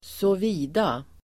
Uttal: [²så:v'i:da]